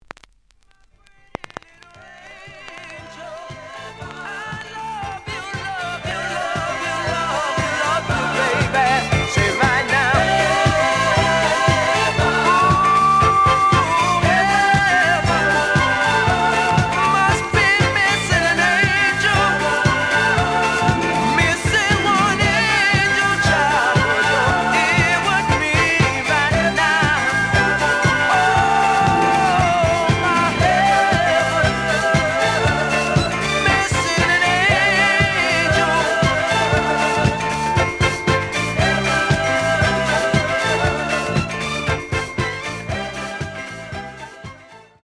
試聴は実際のレコードから録音しています。
The audio sample is recorded from the actual item.
●Genre: Disco